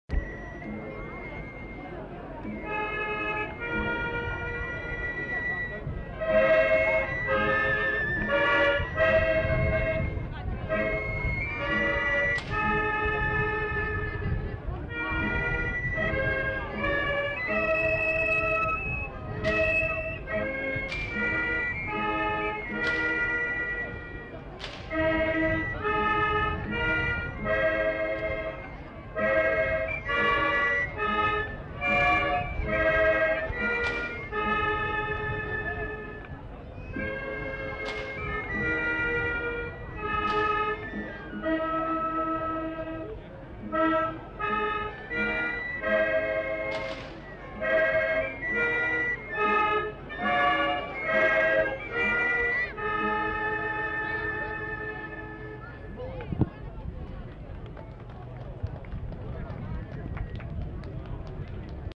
Aire culturelle : Béarn
Lieu : Laruns
Genre : morceau instrumental
Instrument de musique : flûte à trois trous ; tambourin à cordes ; accordéon diatonique